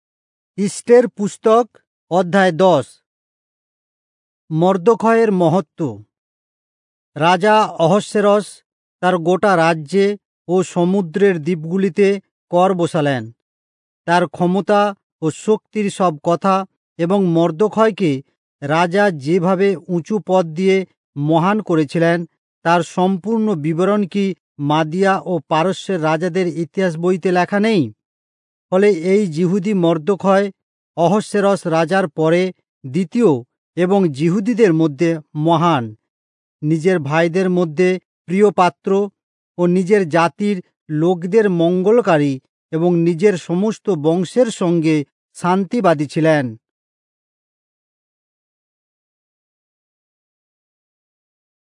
Bengali Audio Bible - Esther 2 in Irvbn bible version